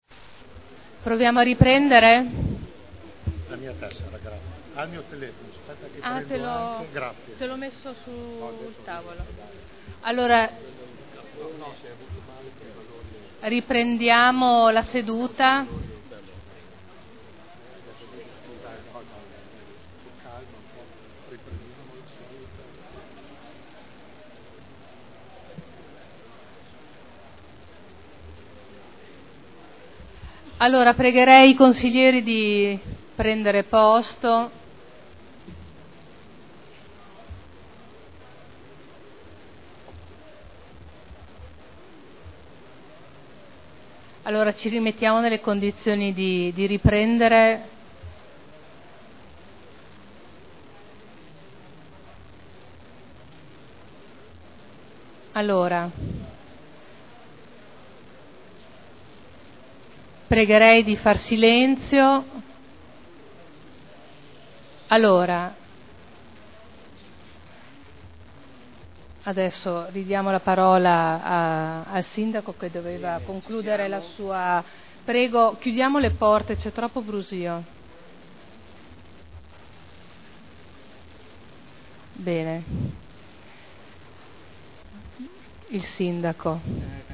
Seduta del 3/02/2011.